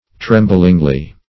-- Trem"bling*ly , adv.